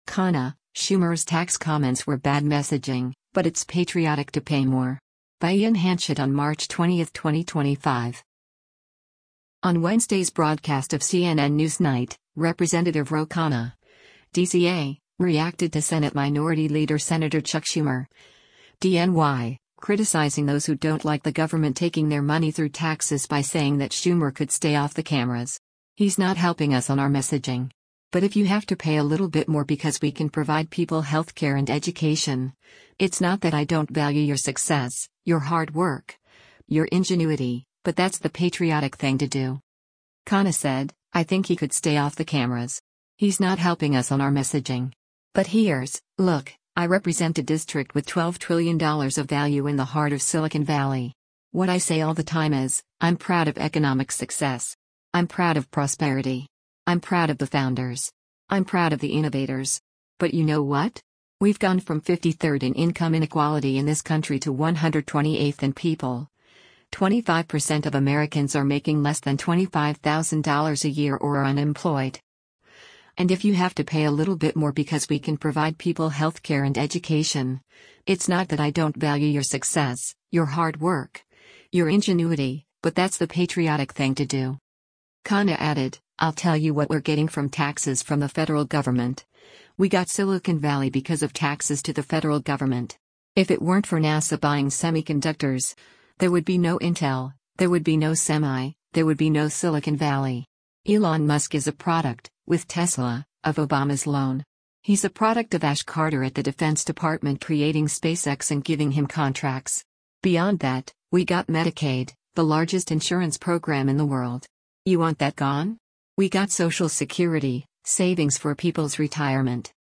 On Wednesday’s broadcast of “CNN NewsNight,” Rep. Ro Khanna (D-CA) reacted to Senate Minority Leader Sen. Chuck Schumer (D-NY) criticizing those who don’t like the government taking their money through taxes by saying that Schumer “could stay off the cameras.